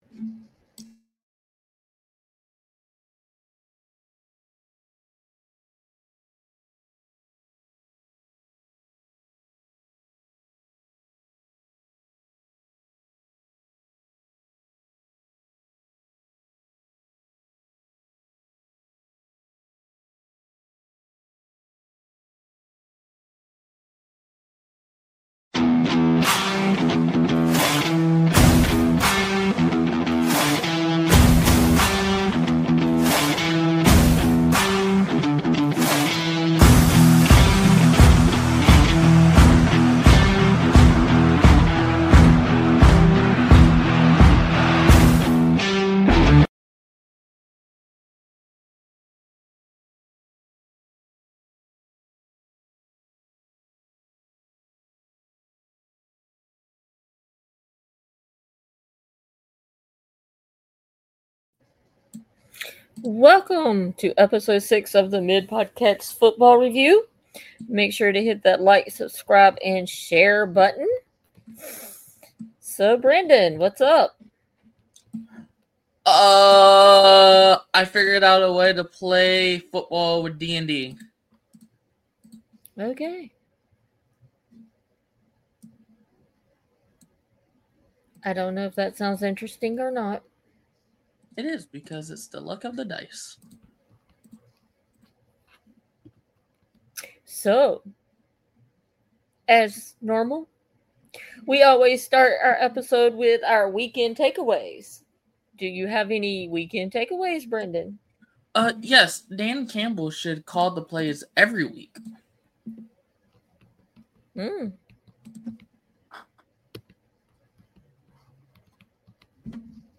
Were a mother and son duo, that love to talk about Geek stuff, Wrestling ,and love talking about random stuff